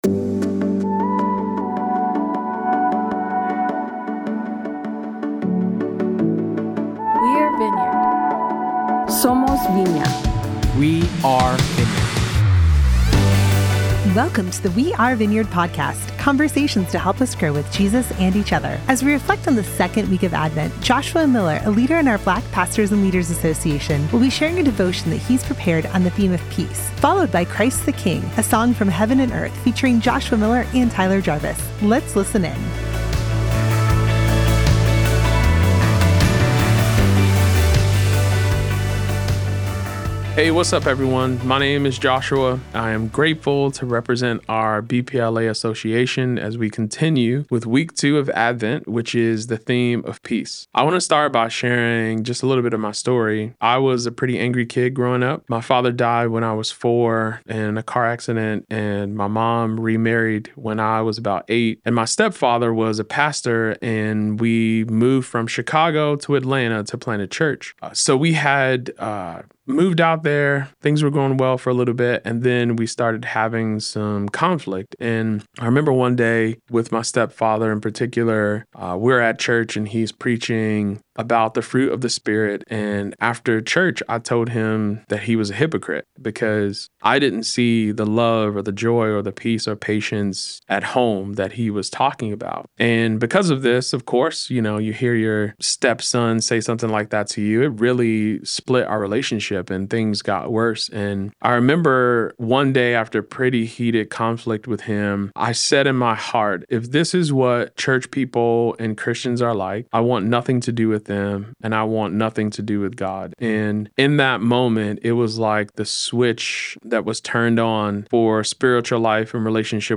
Welcome to the We Are Vineyard podcast. In celebration of the Advent season, you will hear a short devotion each week specially crafted for you by a member of one of Vineyard USA’s Associations.